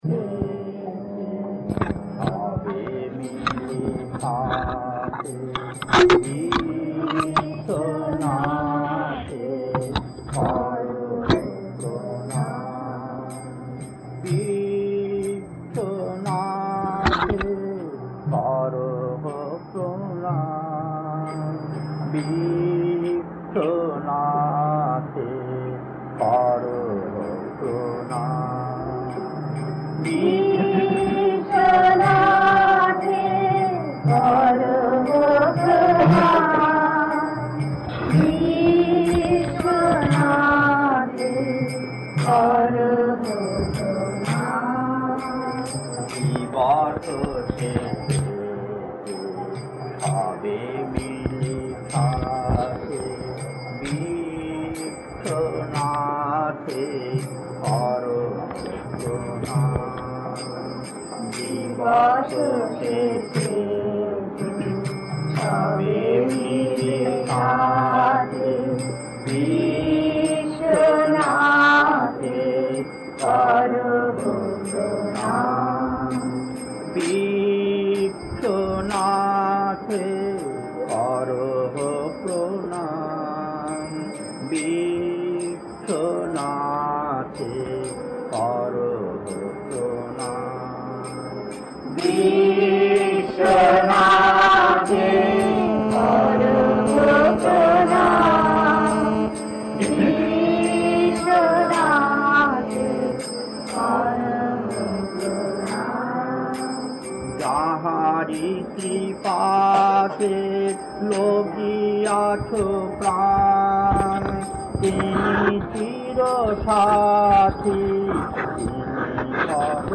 Kirtan C8-2 Kasturi Building, Bombay, 1977, 39 minutes 1.